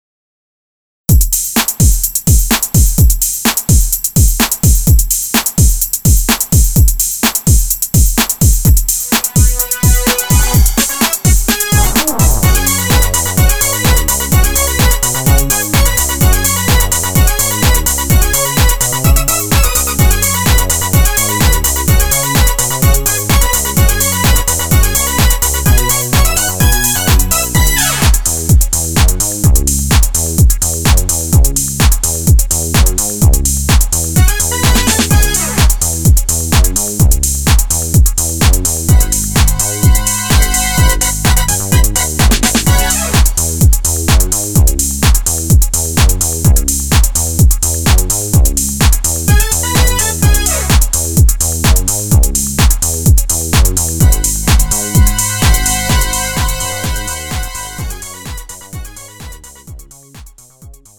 음정 (여자)
장르 가요 구분 Lite MR